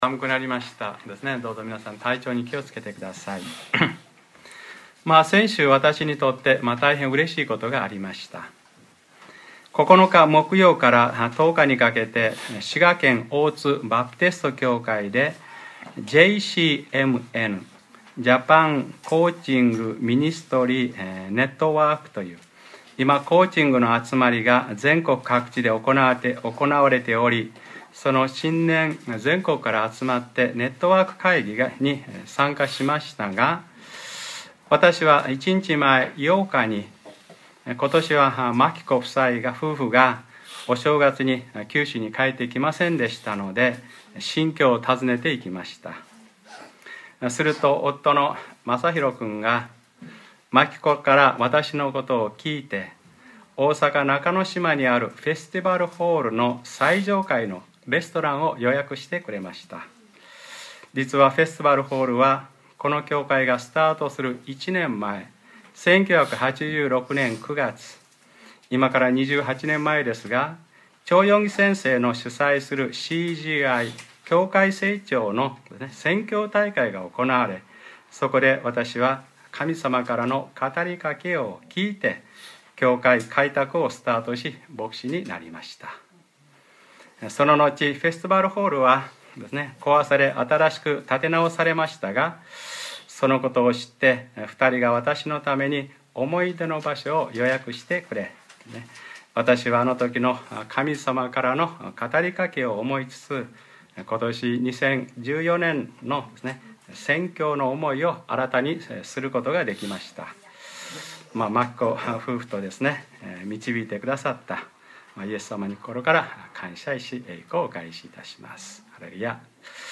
2014年 1月12日（日）礼拝説教『おまえはいつも私といっしょにいる』 | クライストチャーチ久留米教会